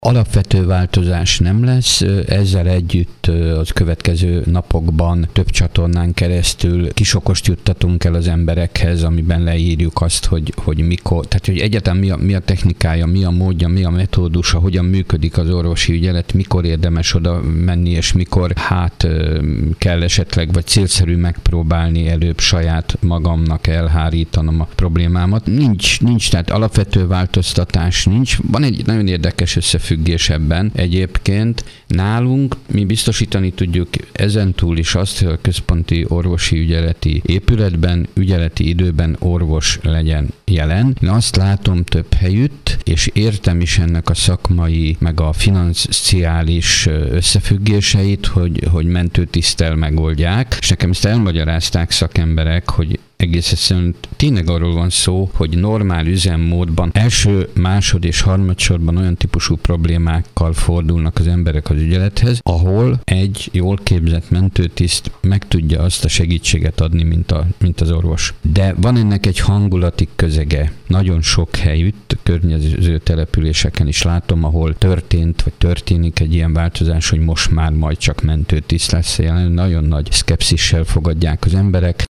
Fazekas László polgármestert hallják.